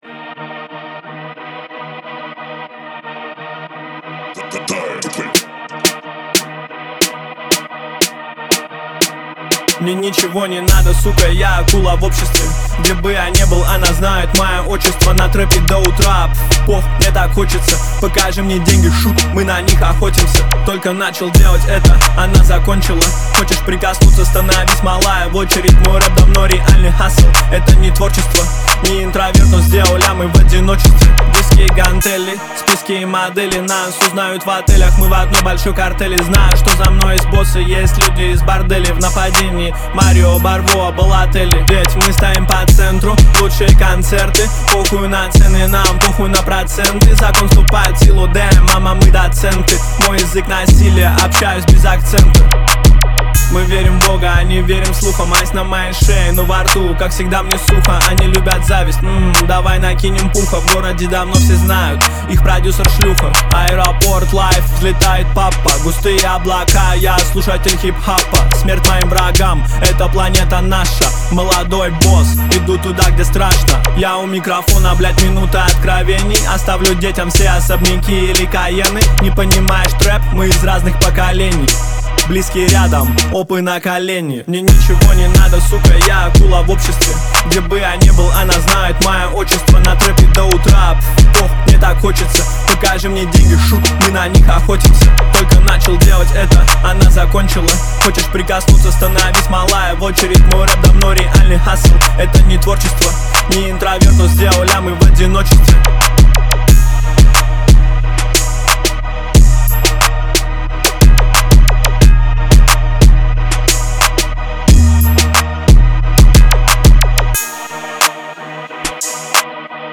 Хип-хоп трек, 2:08.